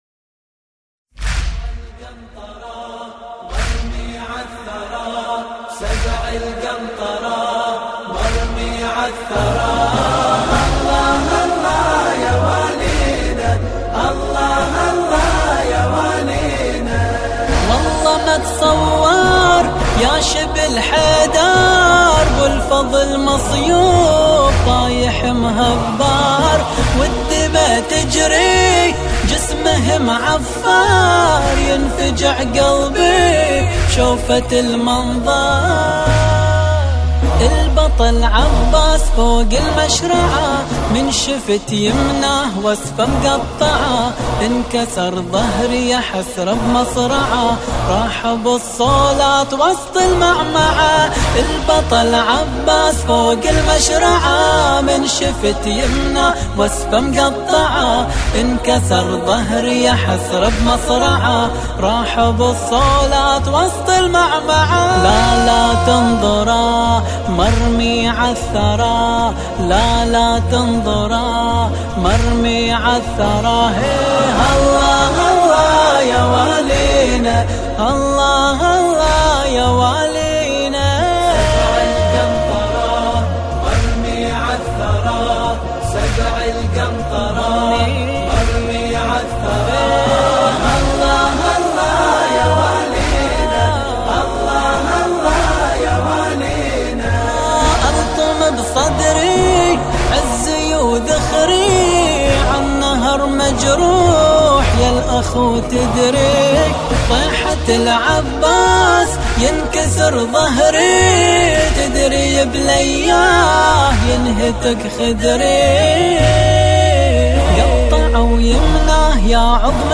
مراثي أبو الفضل العباس (ع)